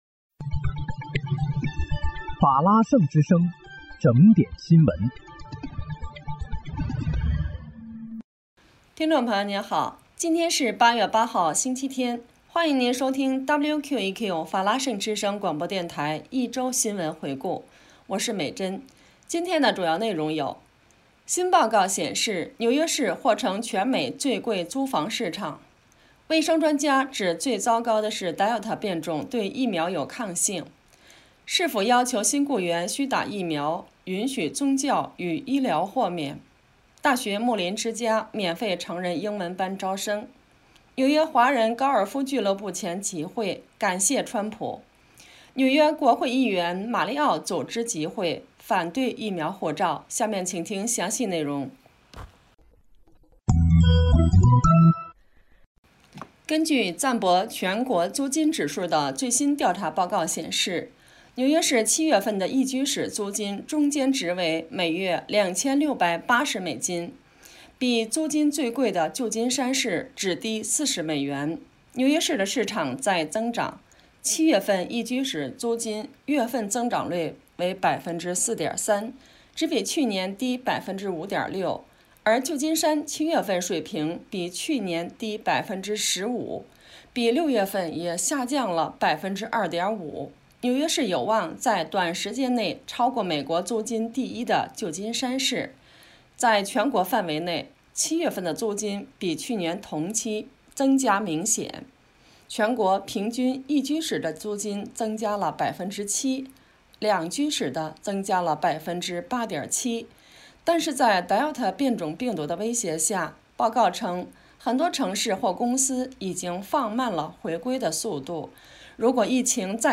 8月8日（星期日）纽约整点新闻